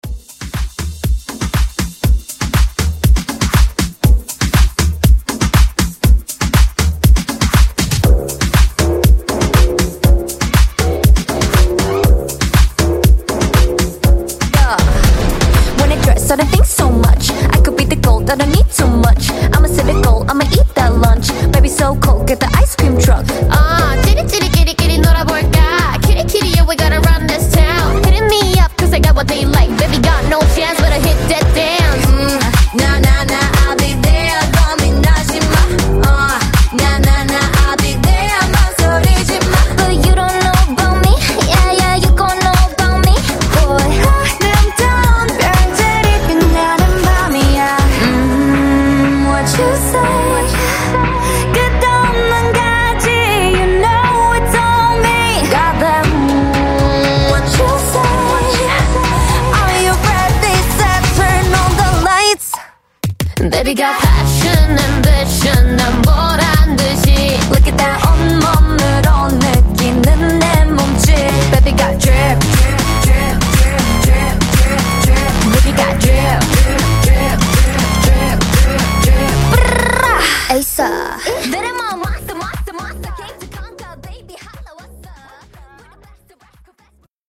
Genres: DANCE , EDM , RE-DRUM
Dirty BPM: 126 Time